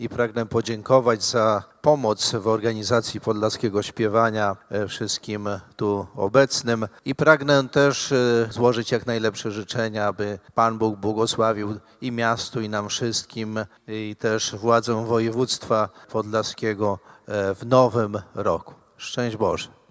Biskup łomżyński Janusz Stepnowski dziękował osobom i instytucjom, które przyczyniły się do organizacji koncertu.